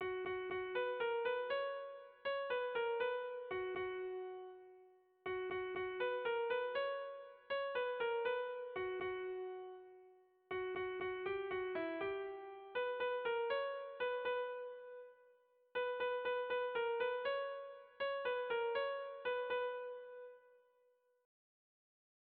Tragikoa
Zortziko txikia (hg) / Lau puntuko txikia (ip)
A-A-B-C